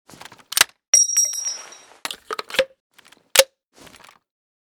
AR_gl_reload.ogg